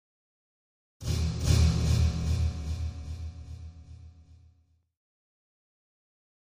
Drum Metallic Hit With Echo 1